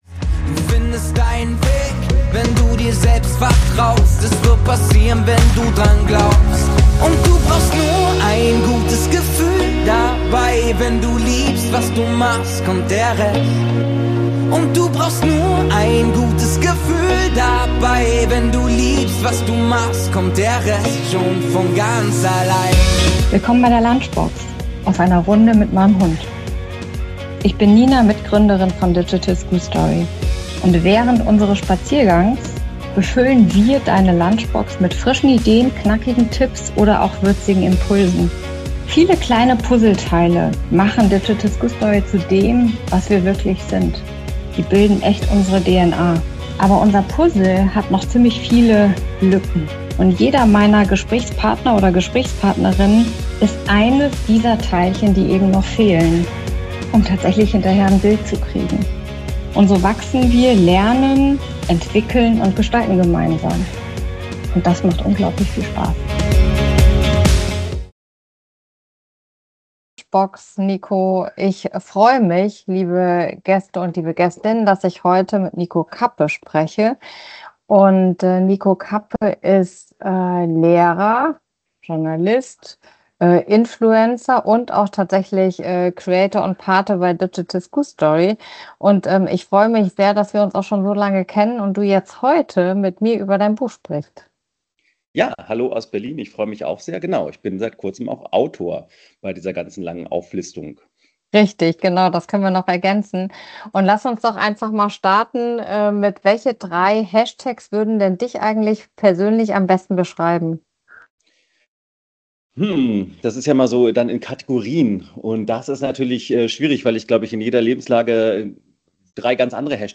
Gemeinsam werfen wir einen kritischen, aber konstruktiven Blick auf den Einfluss digitaler Medien wie TikTok, den Umgang mit Smartphones im Klassenzimmer und die Herausforderungen im Bildungssystem. Warum Verbote nicht helfen, wie Eigenverantwortung gestärkt werden kann und weshalb Medienkompetenz mehr ist als ein Unterrichtsfach – all das kommt zur Sprache. Ein Gespräch über Chancen, Herausforderungen und die Frage, wie Schule junge Menschen wirklich auf die Zukunft vorbereiten kann.